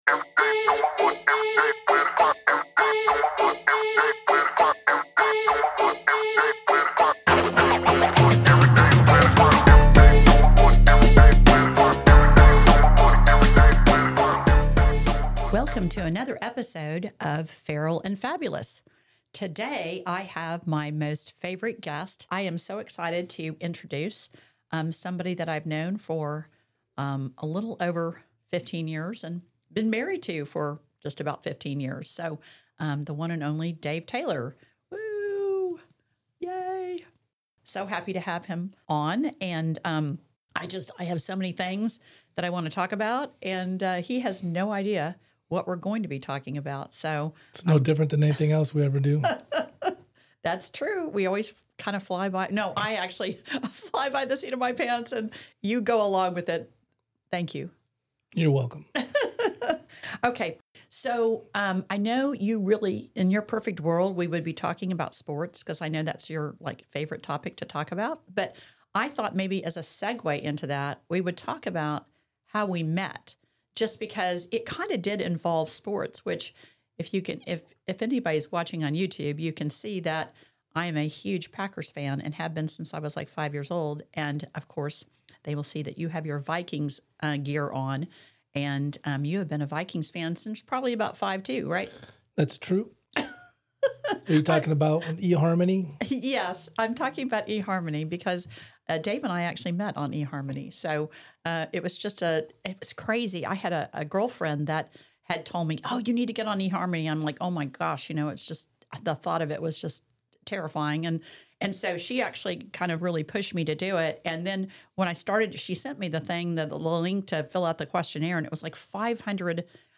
The Feral and Fabulous podcast blends sharp gossip, fresh takes on current events, and insider real estate insights - all with a chic San Antonio flair. It's equal parts stylish conversation and smart commentary, where no topic is considered off limits.